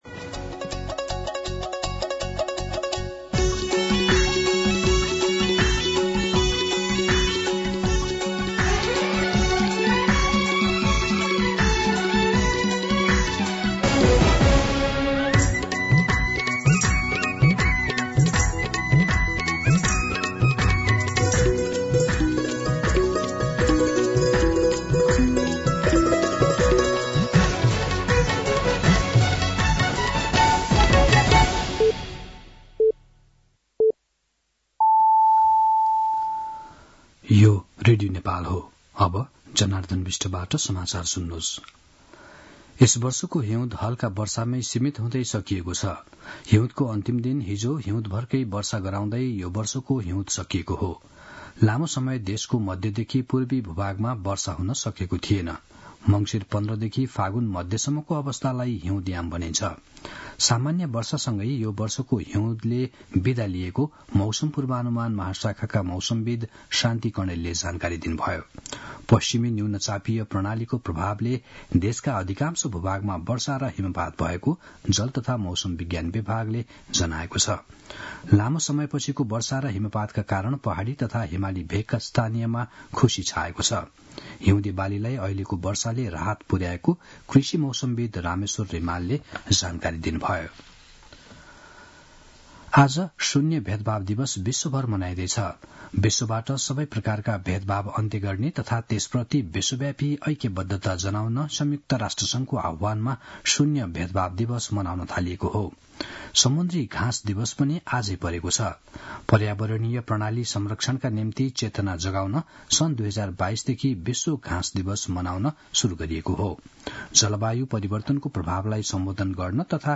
मध्यान्ह १२ बजेको नेपाली समाचार : १८ फागुन , २०८१